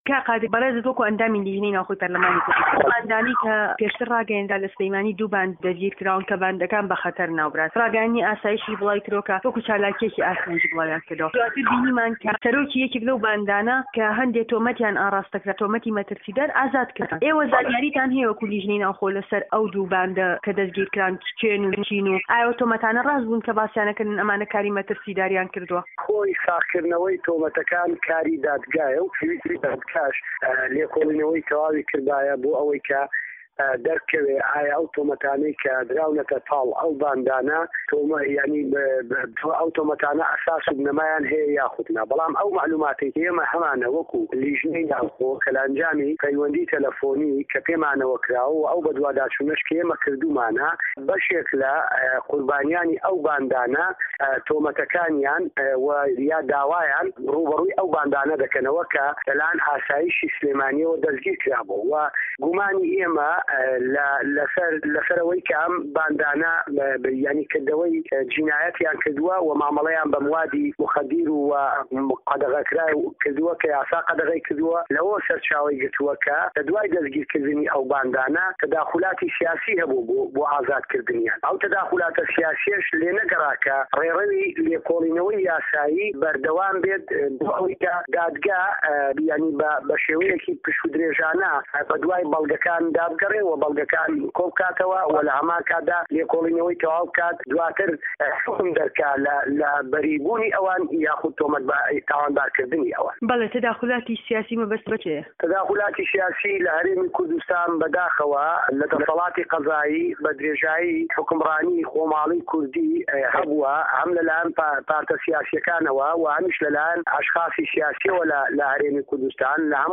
قادر ڕه‌زگه‌یی بڕیارده‌ری لیژنه‌ی ناوخۆ و ئاسایش و ئه‌نجومه‌نه‌ خۆجێییه‌كان له‌ په‌رله‌مانی كوردستان له‌ میانه‌ی وتووێژێكدا له‌گه‌ڵ به‌شی كوردی ده‌نگی ئه‌مەریكا ده‌ڵێت به‌ ته‌داخولی حیزبی سیاسی و خودی لێپرسراوی ئه‌نجومه‌نی سه‌ركردایه‌تی پارتی دیموكراتی كوردستان ڕێگه‌ نه‌درا دادگا به‌شێوه‌یه‌كی پشوودرێژانه‌ له‌و دوو بانده‌ی ده‌ستگیركرابوو بكۆڵێته‌وه‌